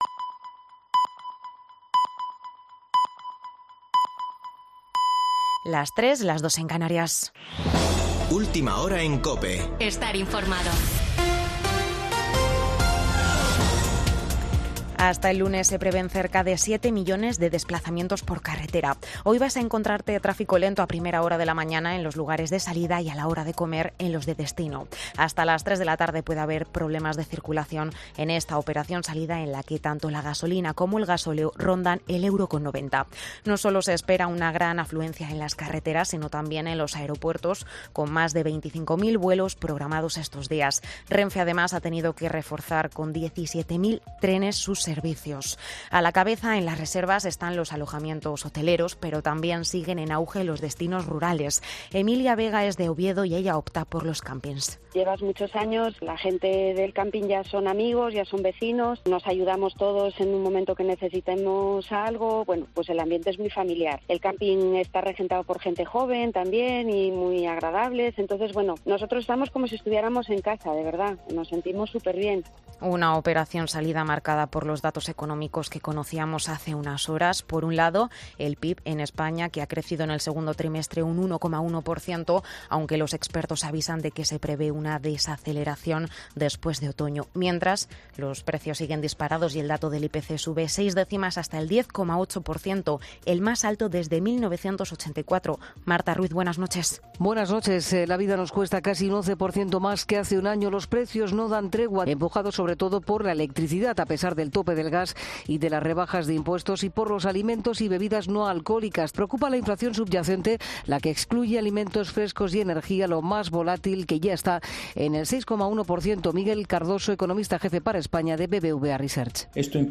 Boletín de noticias de COPE del 30 de julio de 2022 a las 03:00 horas